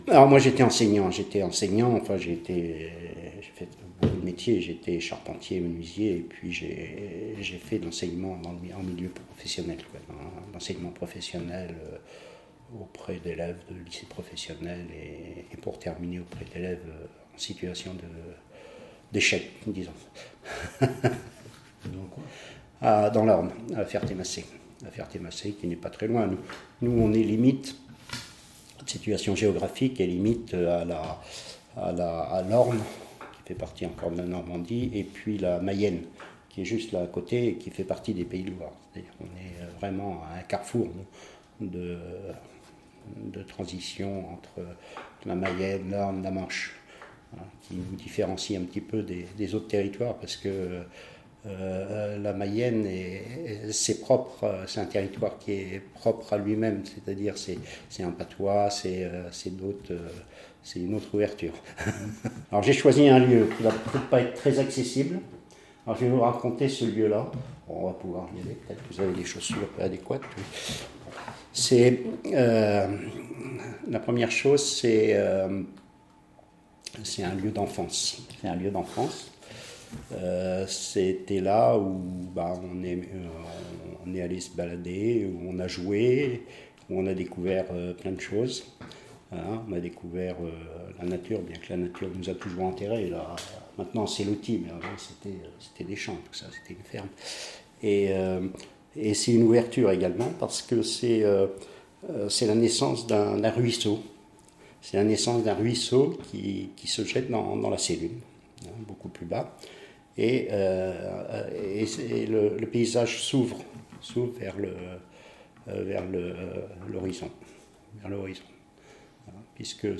Portraits et entretiens